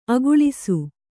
♪ aguḷisu